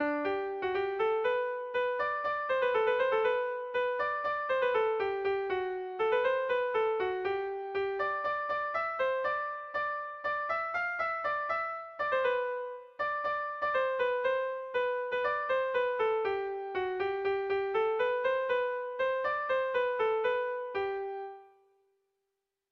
Gabonetakoa
ABDE